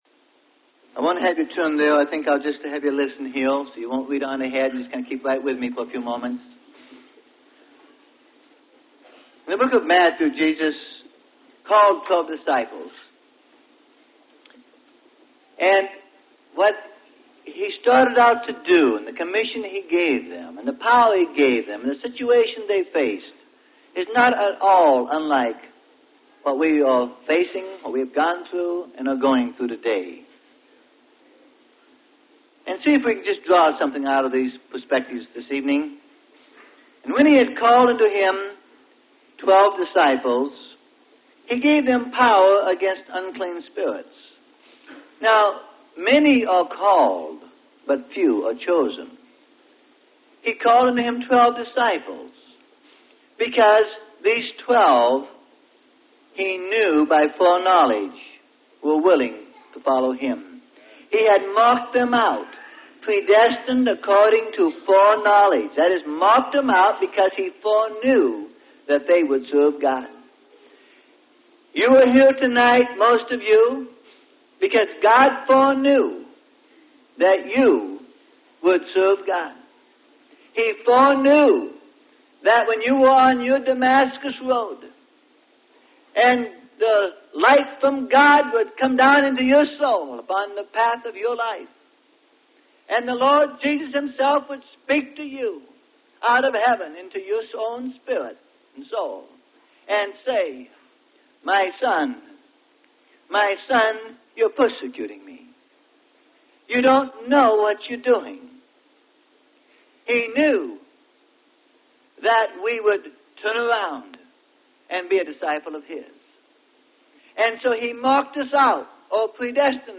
Sermon: Jesus Wants Full Discipleship - Freely Given Online Library